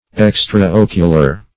Search Result for " extra-ocular" : The Collaborative International Dictionary of English v.0.48: Extra-ocular \Ex`tra-oc"u*lar\, a. (Zo["o]l.)